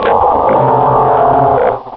Cri de Wailord dans Pokémon Rubis et Saphir.
Cri_0321_RS.ogg